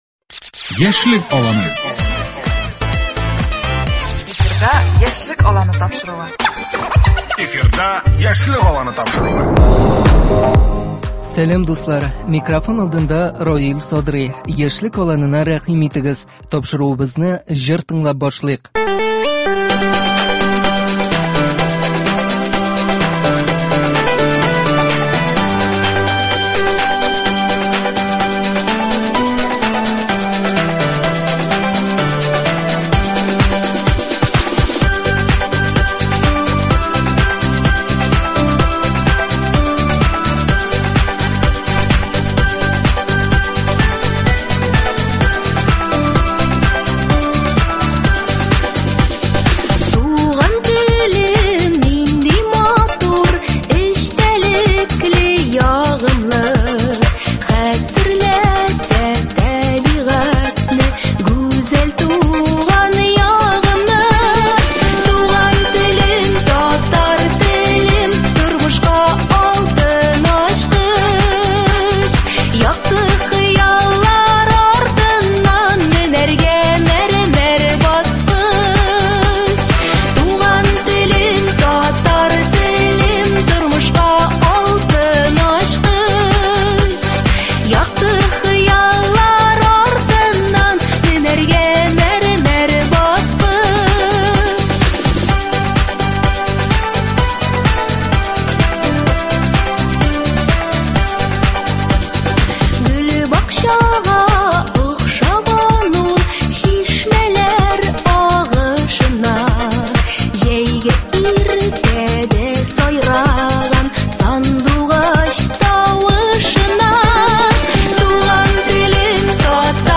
Бүген бездә кунакта туган тел сагында торган яшьләр – булачак татар теле һәм әдәбияты укытучылары.
Бүген безнең студиядә менә шушы мактаулы конкурста катнашып гран-при яулаган һәм җиңүче булган студентлар.